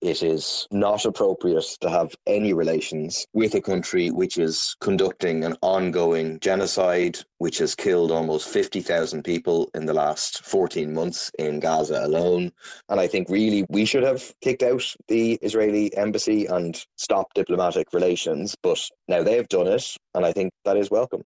People Before Profit TD Paul Murphy says it’s a welcome development: